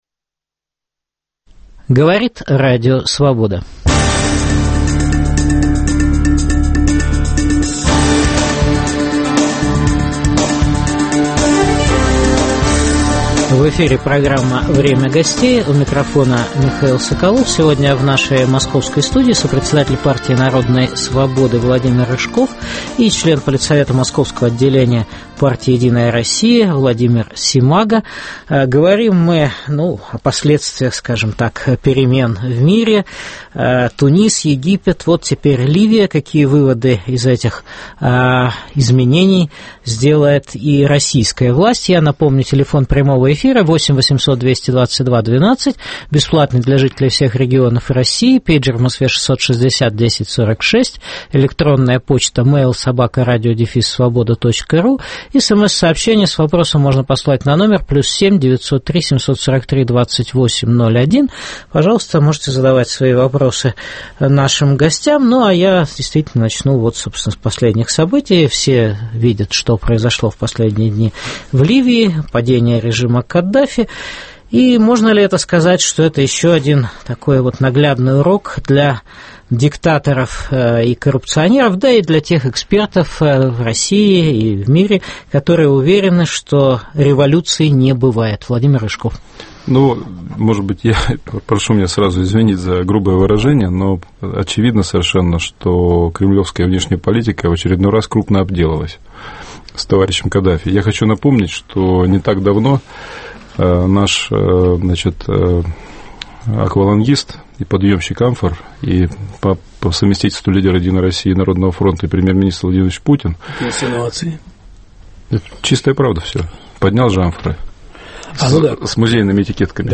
Какие выводы сделает российская власть? В программе о политической ситуации в России дискутируют: сопредседатель Партии народной свободы Владимир Рыжков и член московского регионального политсовета партии "Единая Россия" Владимир Семаго.